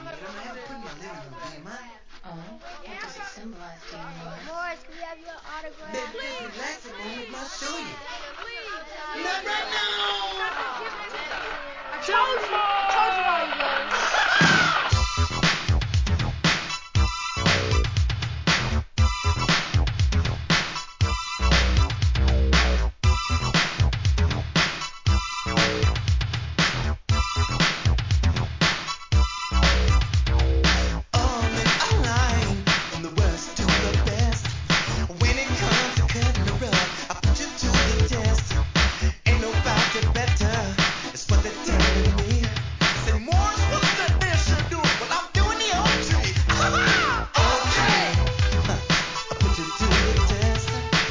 SOUL/FUNK/etc...
ミネアポリス・ファンク!!